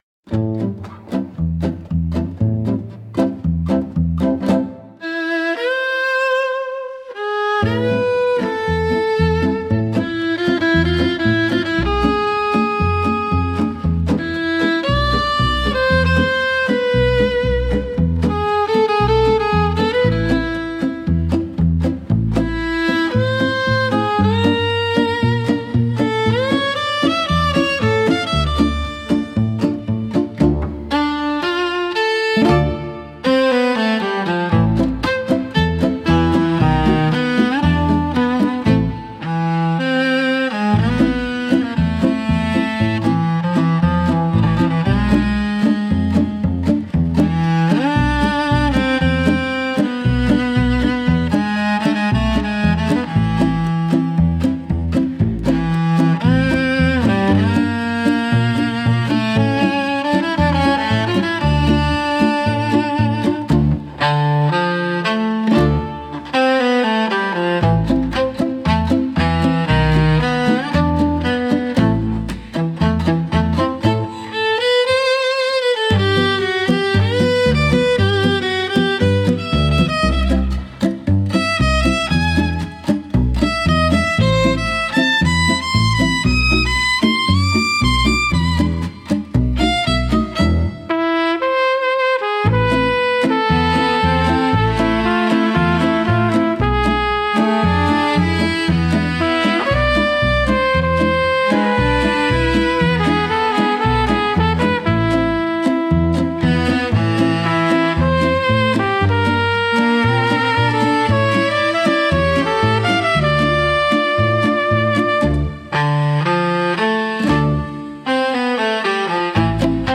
música e arranjo IA) instrumental 8